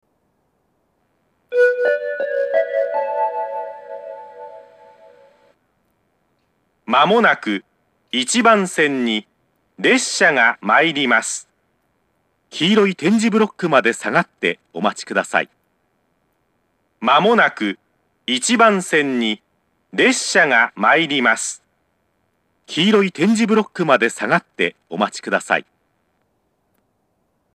１番線接近放送
収録の際は千葉寄りだと環境が良いです。